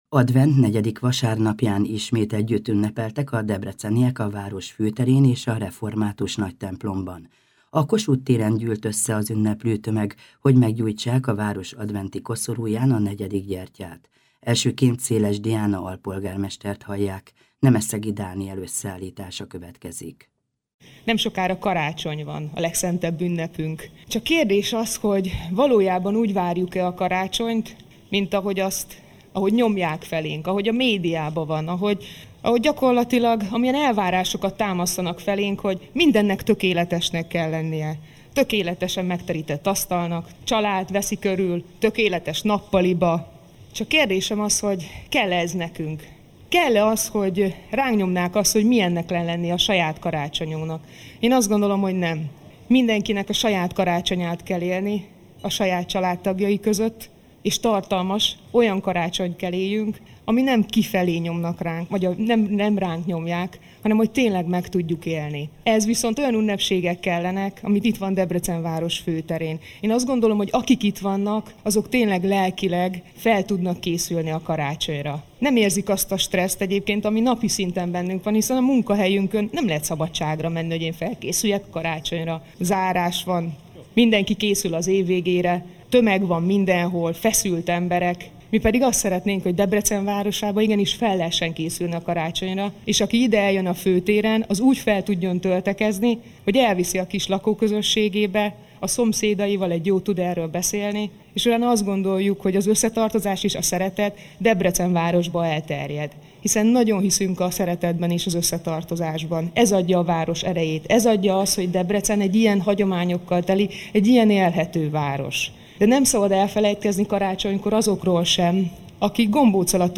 Debrecenben a negyedik adventi gyertyagyújtás vasárnapján is a Régi Városházától indult az énekes hagyományőrző menet a Nagytemplom elé.